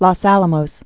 (lôs ălə-mōs, lŏs)